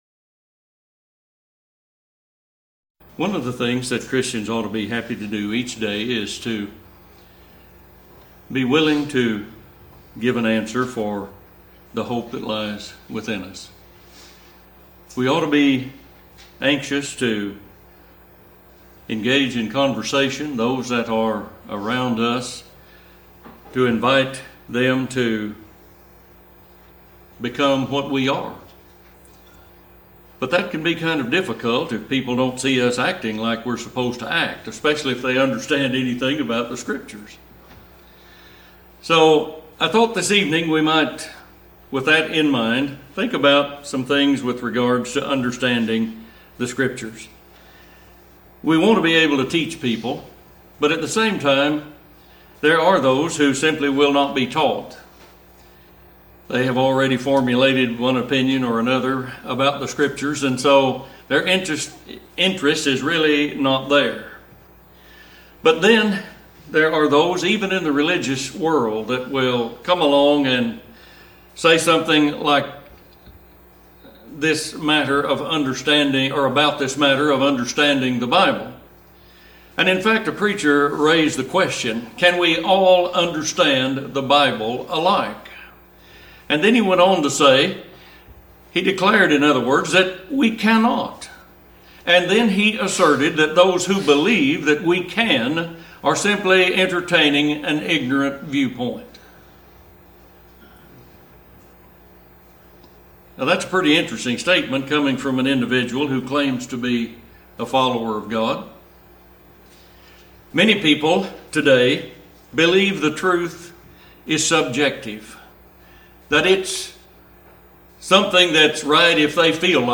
Understanding the Bible Sermon Title Speaker Date Time Can We Understand the Bible Alike?